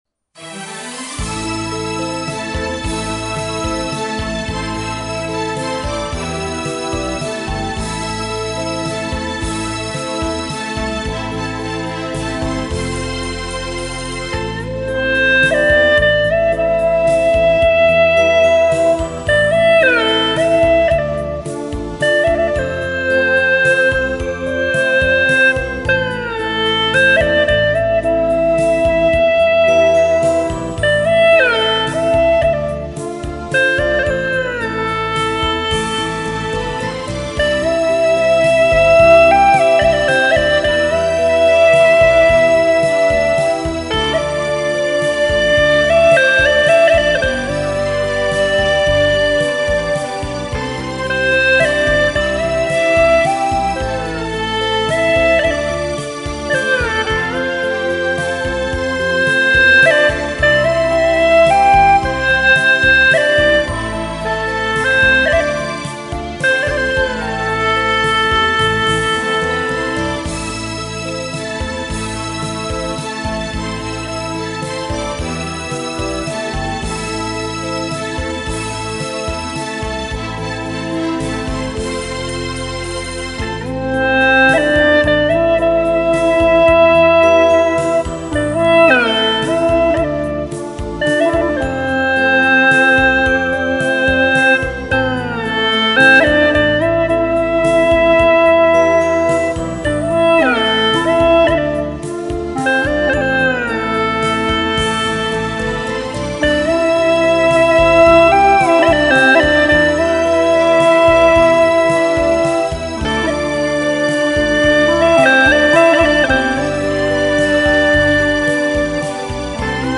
调式 : C 曲类 : 红歌
抒情的旋律，富有动感的节奏，表达了红军到来时彝族同胞的喜悦心情。